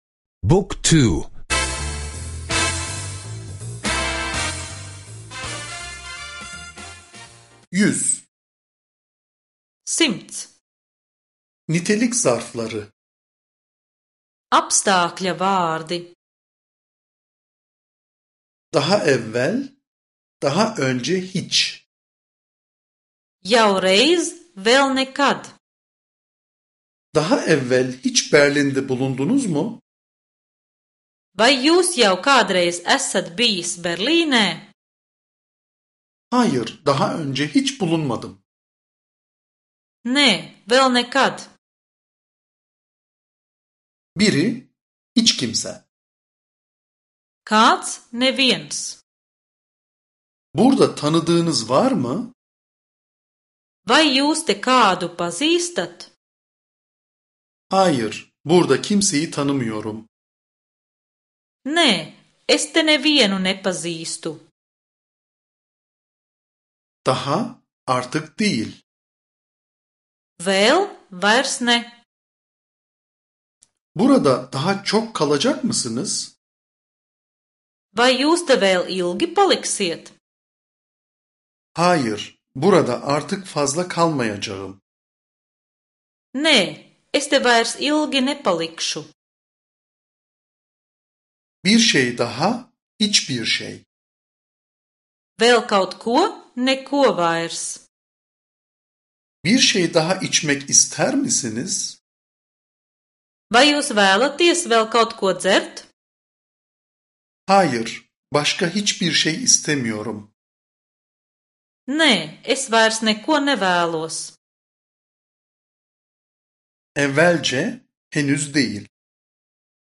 Sesli Letonca kursu